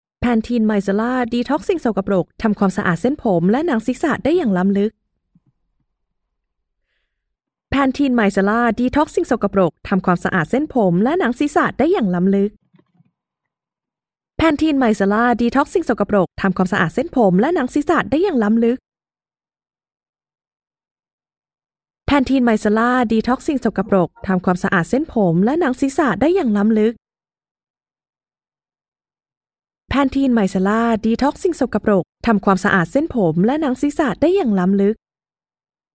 女泰语02
女泰语02 泰语女声 干音 大气浑厚磁性|沉稳|娓娓道来|积极向上|时尚活力|神秘性感|调性走心|亲切甜美